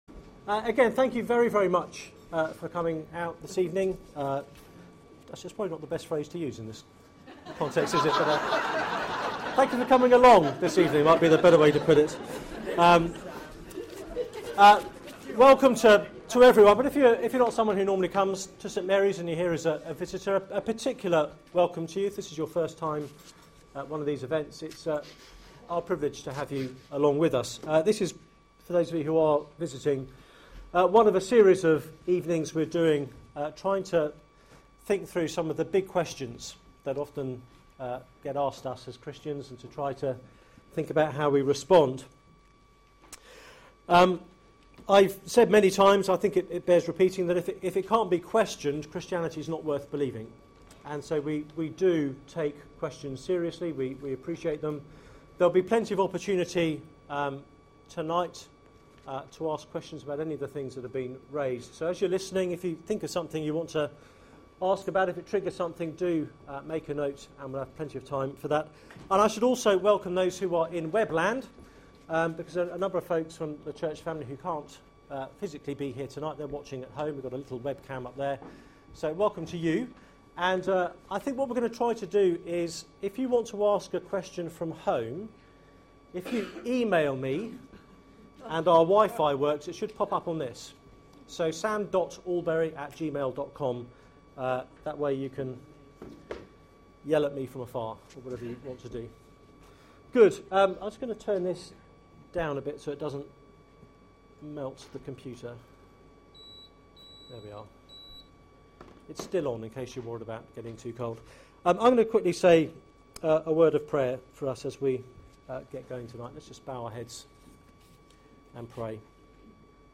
Media for Seminar on Mon 04th Mar 2013 20:00 Speaker